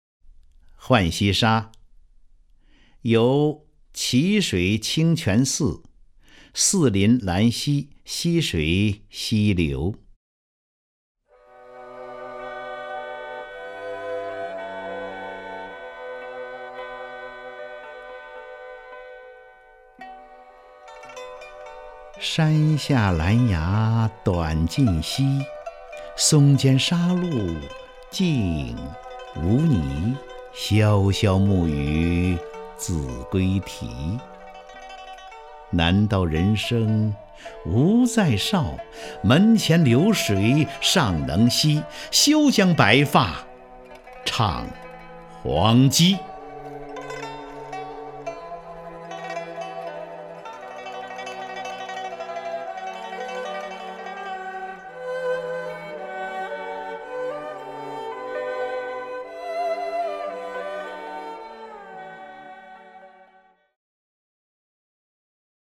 张家声朗诵：《浣溪沙·游蕲水清泉寺》(（北宋）苏轼)
名家朗诵欣赏 张家声 目录